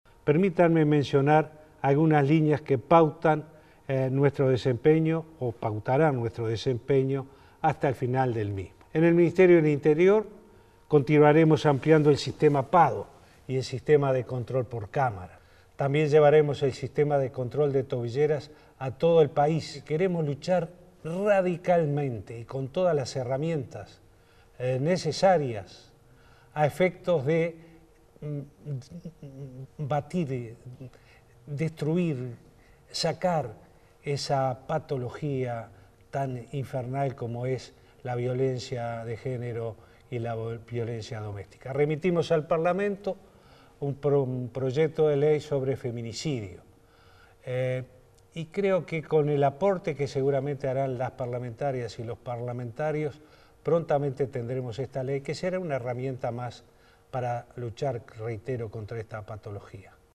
“Queremos luchar con todas las herramientas necesarias a fin de destruir esa patología tan infernal como es la violencia de género”, sostuvo Vázquez durante su mensaje a la población. Dijo que se llevará el sistema de tobilleras a todo el país y subrayó que el Gobierno remitió al Parlamento un proyecto de ley sobre femicidio.